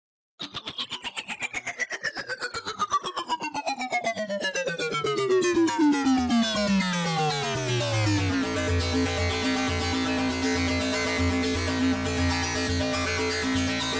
描述：处理过的合成器riff（向下）
标签： 电子乐 声景 合成器
声道立体声